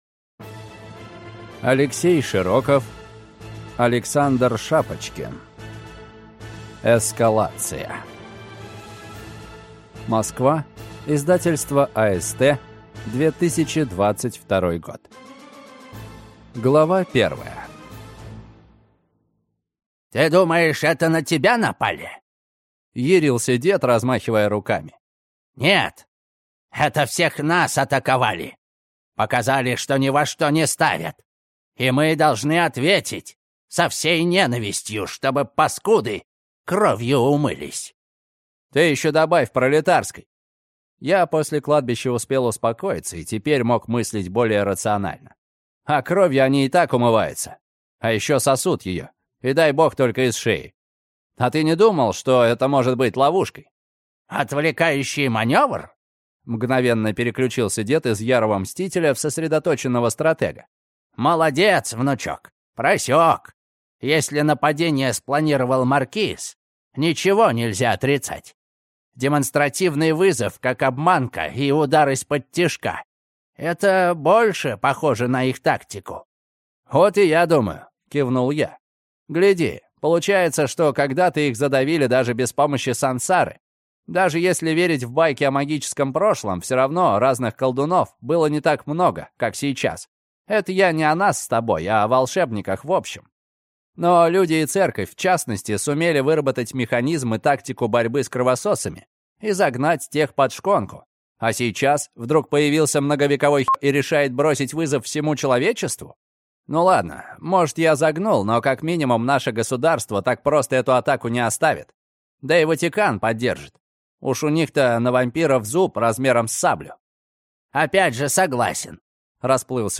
Аудиокнига Эскалация | Библиотека аудиокниг